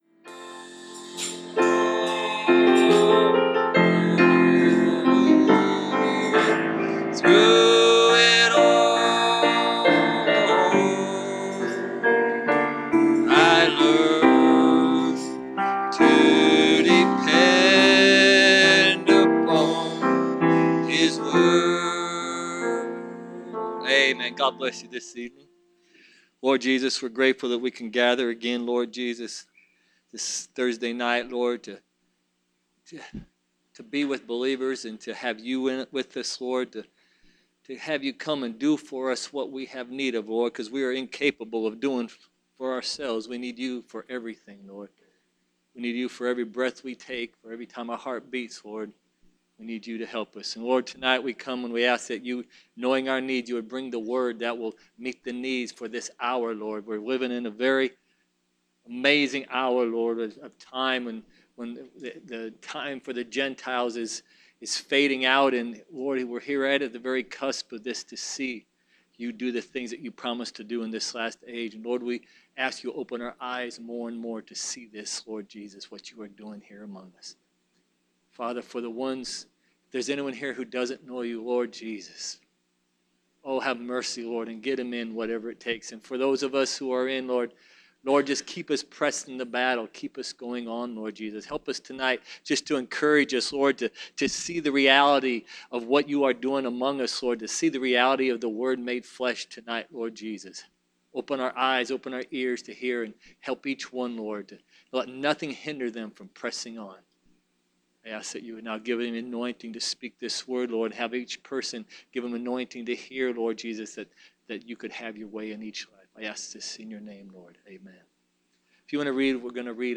Preached March 29, 2018